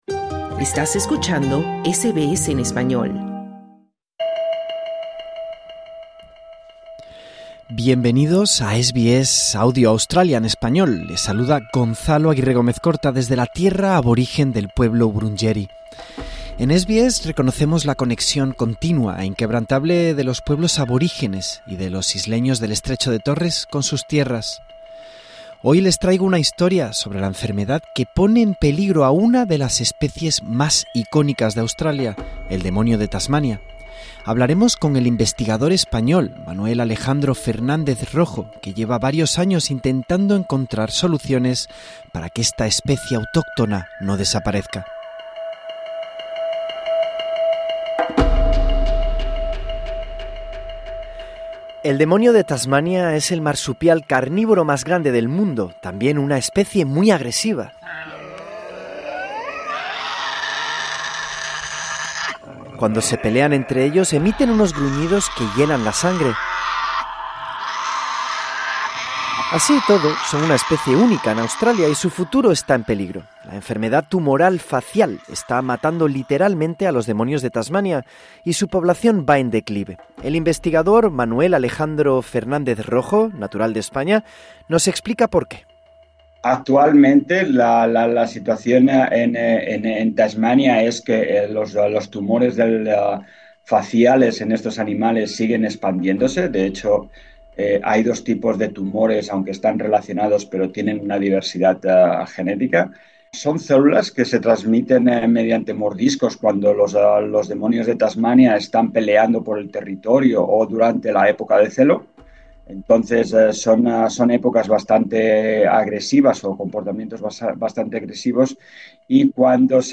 Así es el bramido del demonio de Tasmania que cada vez cuesta más trabajo oír en Australia